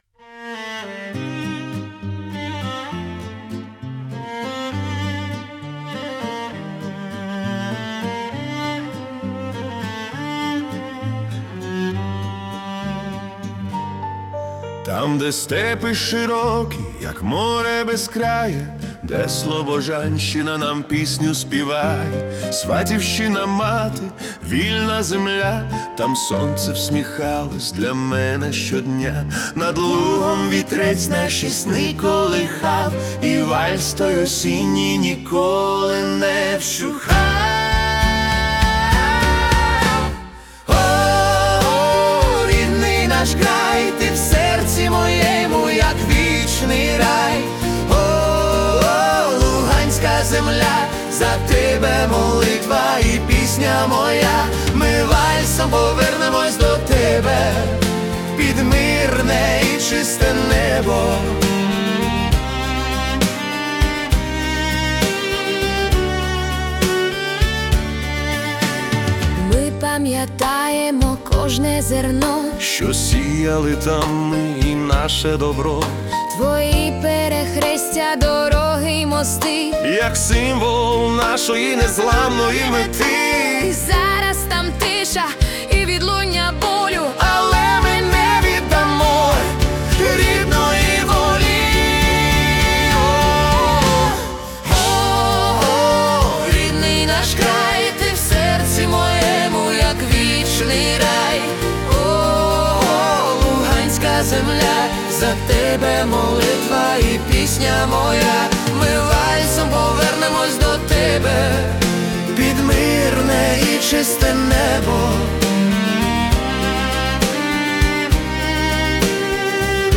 🎵 Жанр: Cinematic Waltz